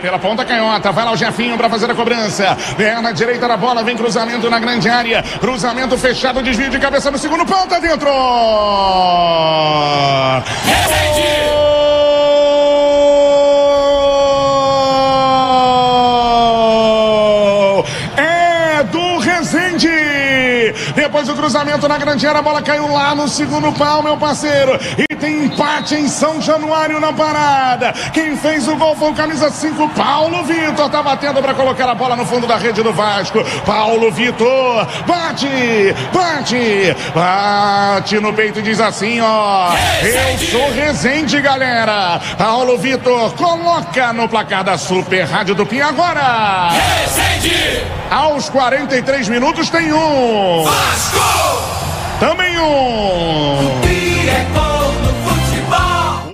GOL-RESENDE-1-X-1-VASCO.mp3